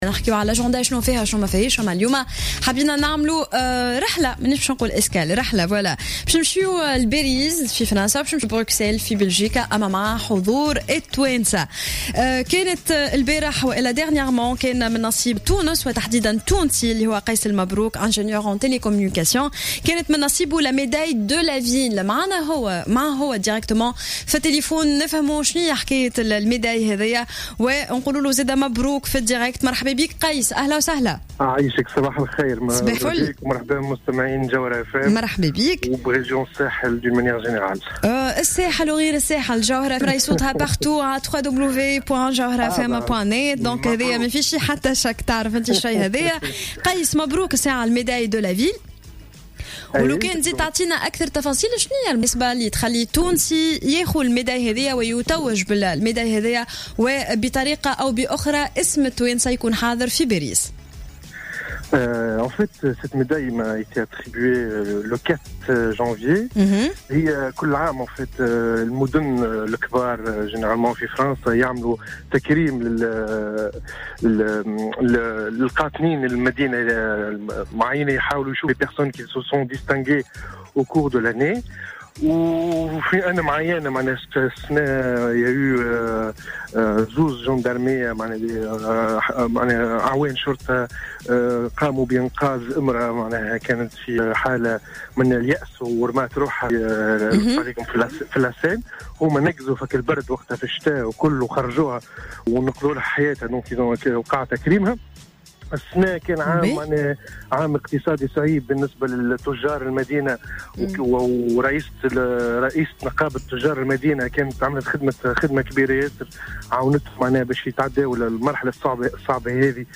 مداخلة له على الجوهرة "اف ام"